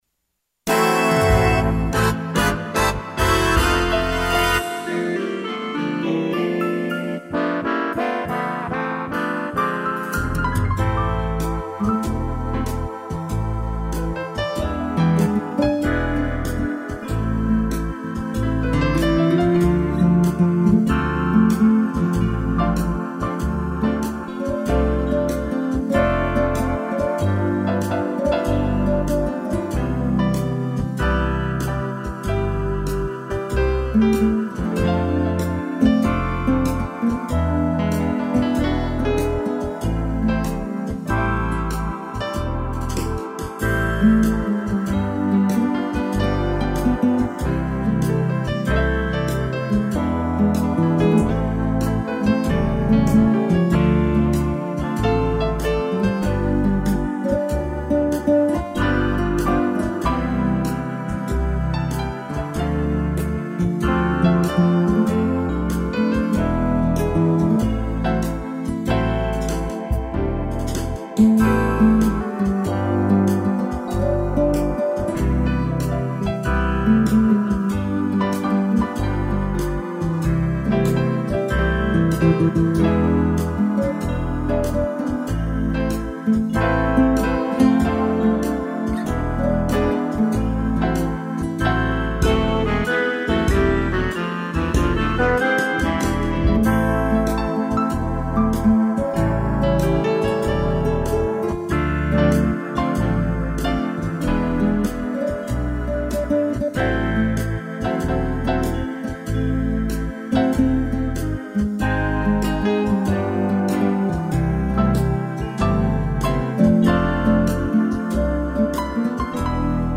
piano
(intrumental)